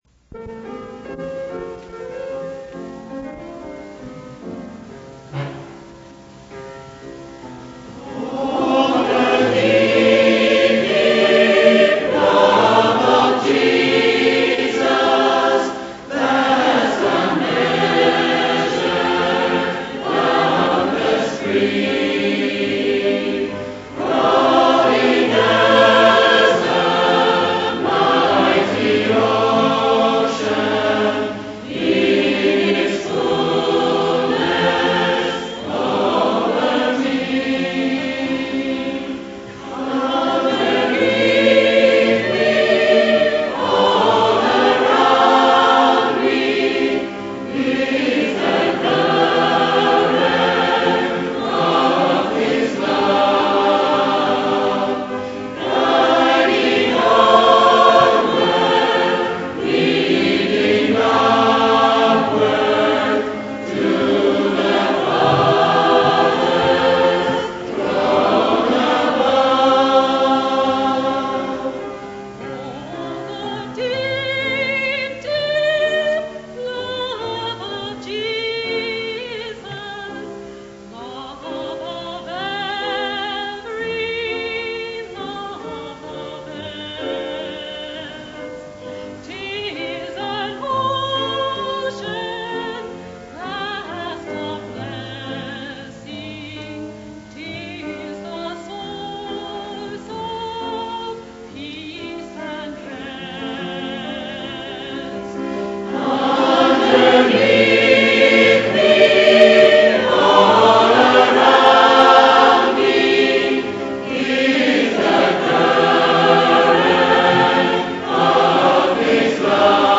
Song_Service_-_Choir_Music.mp3